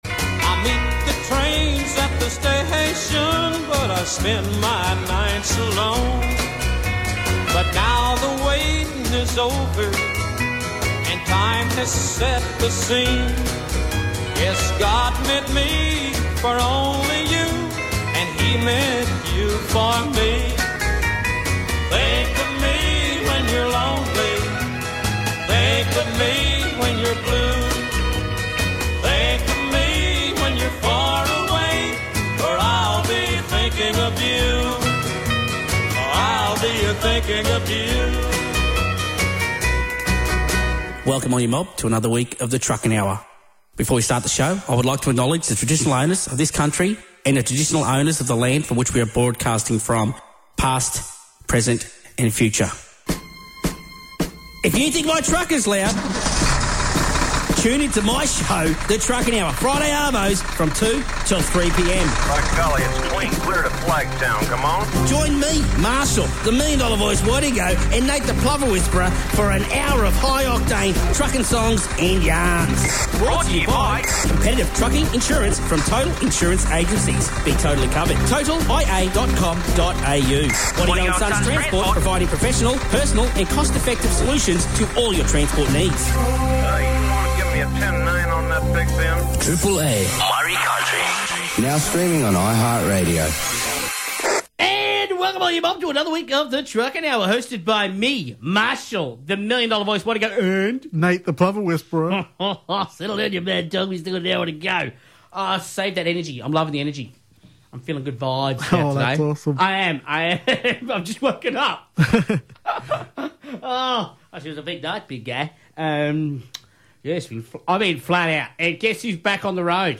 We’ll be playing all your truckin’ favorites with great banter!